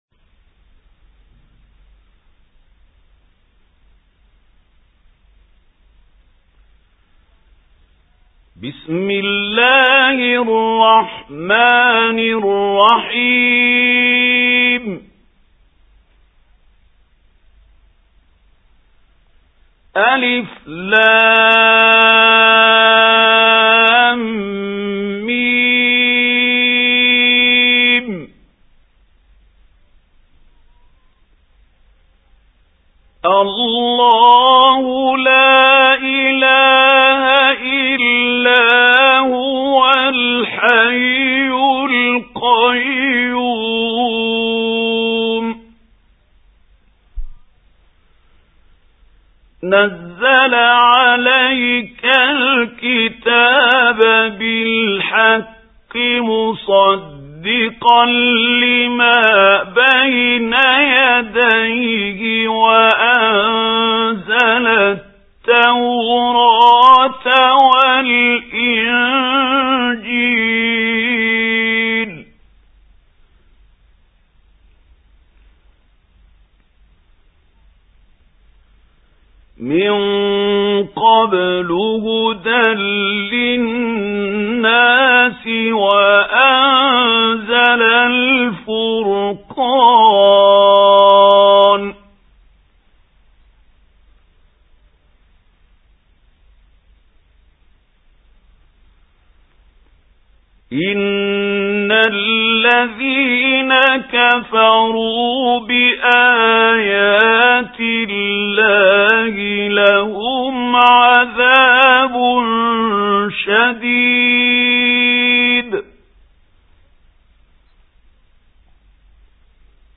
سُورَةُ ٓآلِ عِمۡرَانَ بصوت الشيخ محمود خليل الحصري